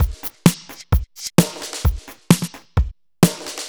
Breaks Or House 01.wav